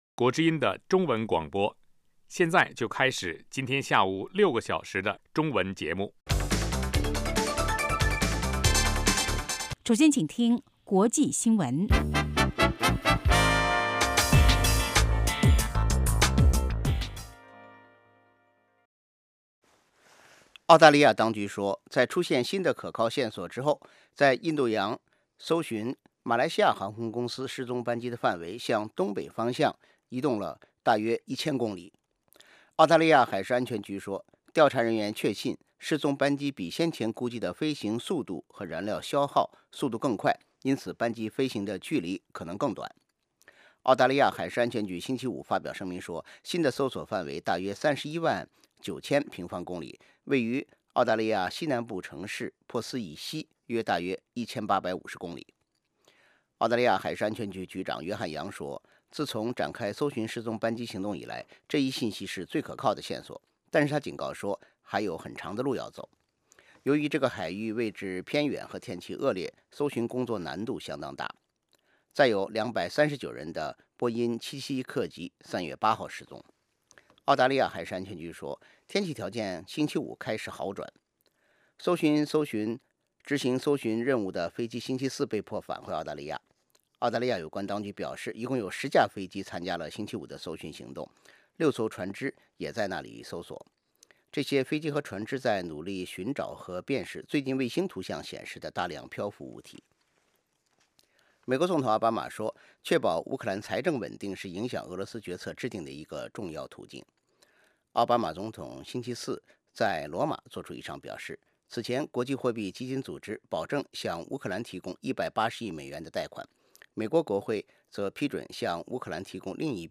国际新闻 英语教学 社论 北京时间: 下午5点 格林威治标准时间: 0900 节目长度 : 60 收听: mp3